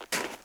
snowFootstep02.wav